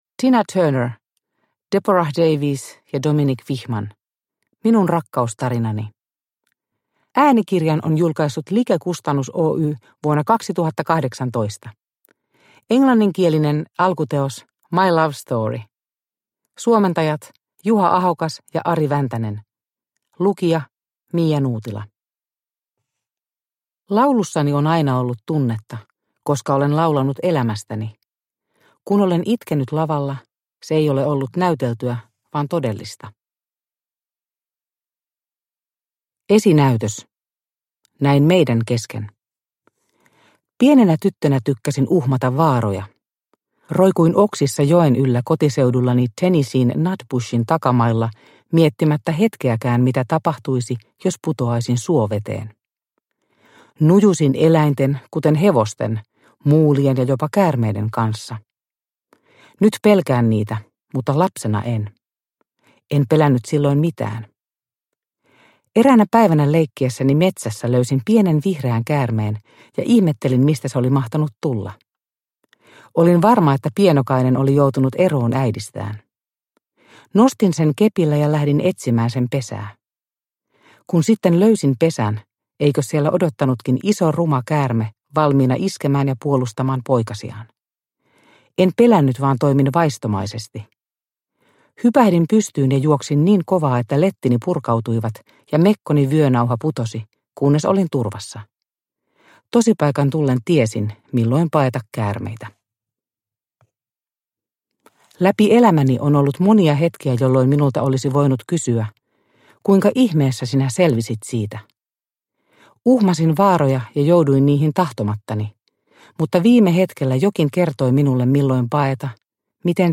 Minun rakkaustarinani – Ljudbok